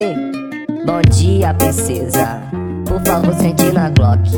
Memes
Bom Dia Princesa High Pitch